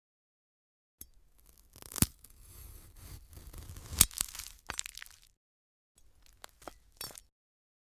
The most underrated fruit for ASMR